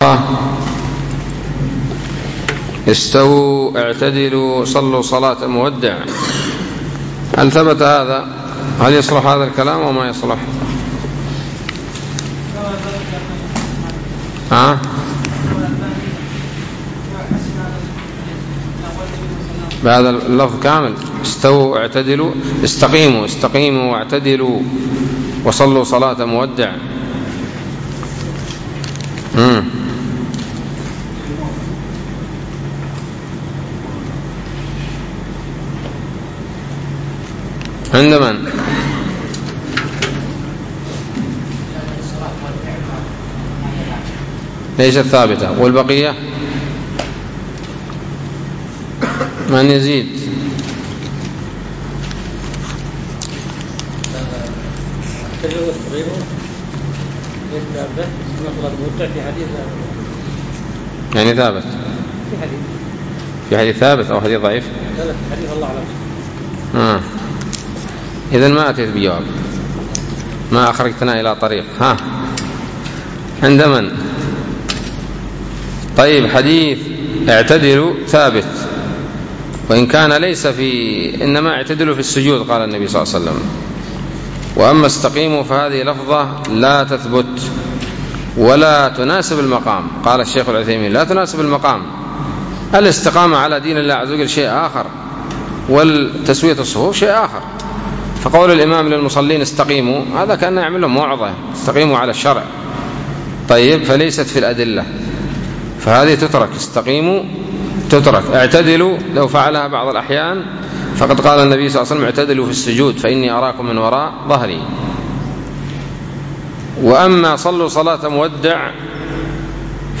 الدرس الخامس والأربعون من شرح العقيدة الواسطية